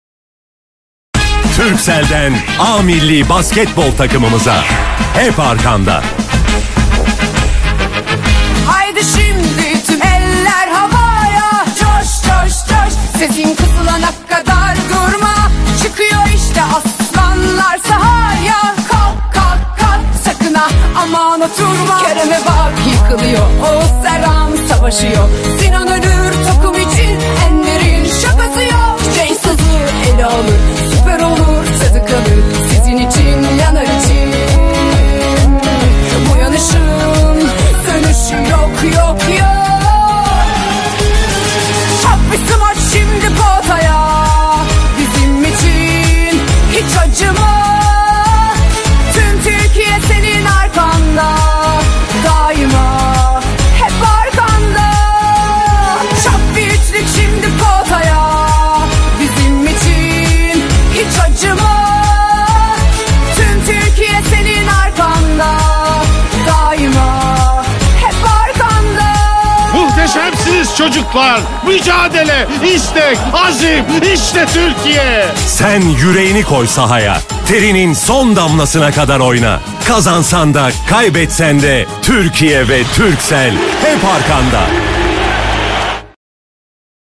Milli Basketbol Takımı reklamı